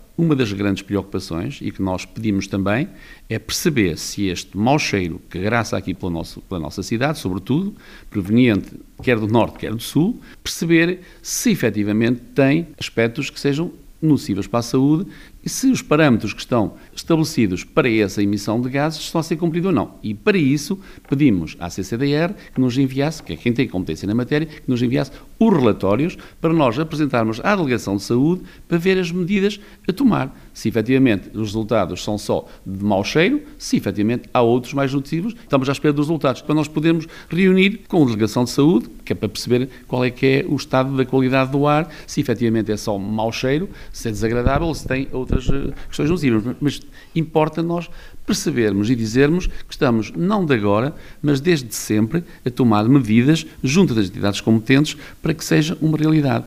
O autarca afirma que as unidades industriais estão a aplicar essas necessidades de melhoria, “só que não conseguem fazê-lo de um dia para o outro e percebemos que só conseguem ter garantias que a partir de novembro de 2025, na próxima época produtiva, que estejam já essas medidas implementadas”, acrescenta.